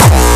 VEC3 Bassdrums Dirty 15.wav